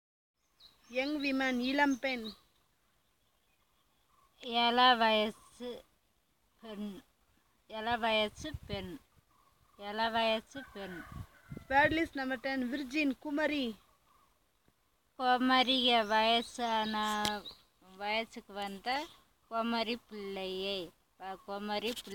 NotesThis is an elicitation about stages of life, using the SPPEL Language Documentation Handbook.